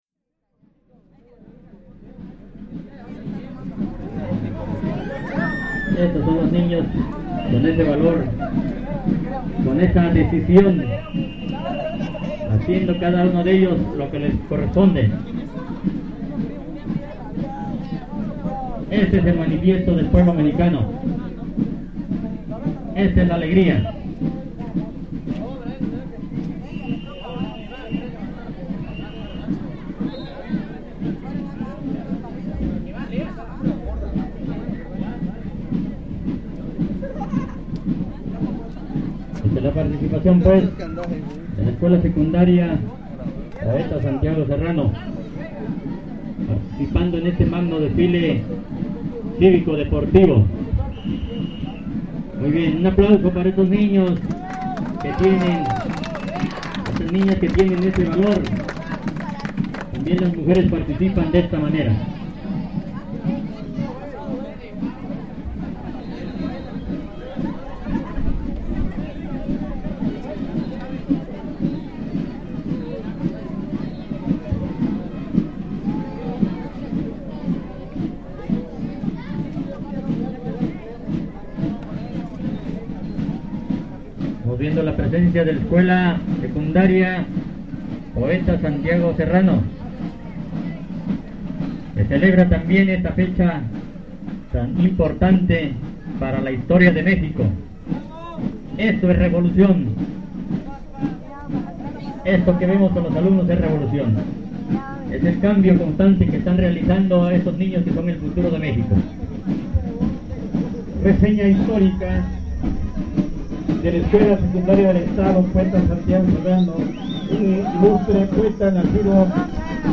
En este archivo sonoro podemos escuchar la narración y el ambiente de los asistentes al desfile realizado en Suchiapa, Chiapas. Mexico.